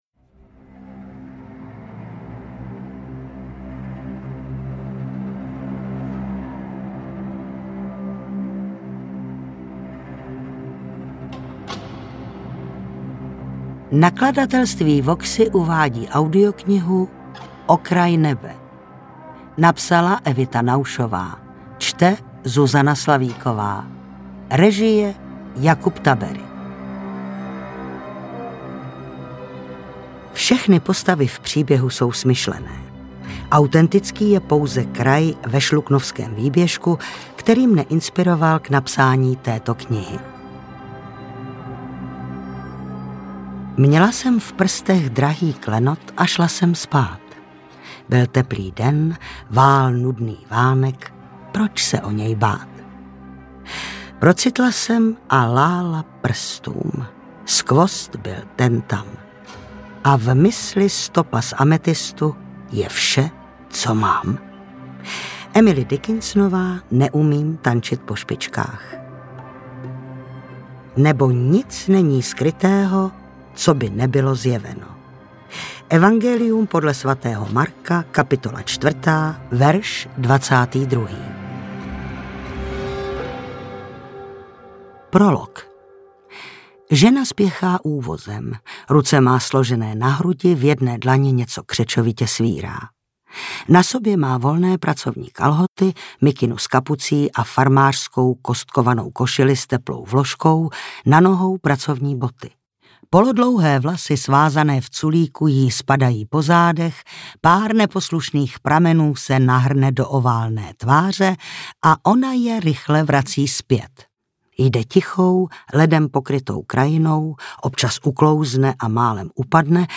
Interpret:  Zuzana Slavíková
AudioKniha ke stažení, 61 x mp3, délka 12 hod. 38 min., velikost 689,0 MB, česky